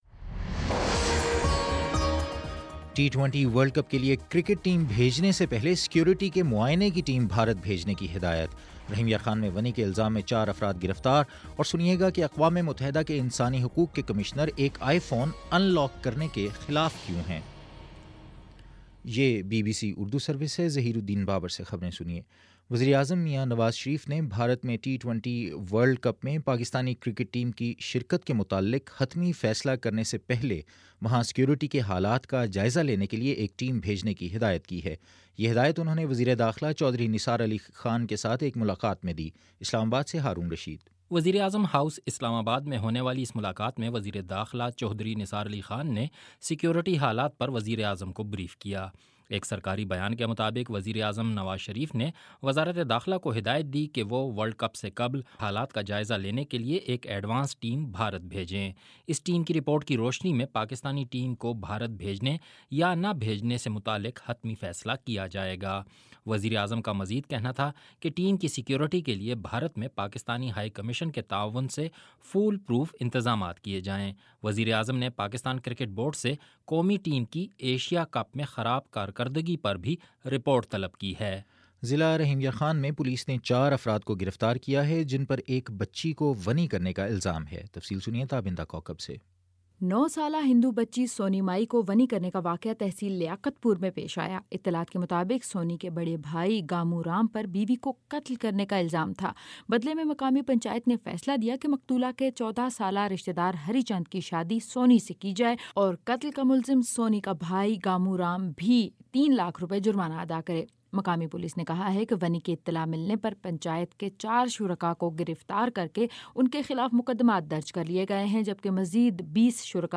مارچ 04 : شام چھ بجے کا نیوز بُلیٹن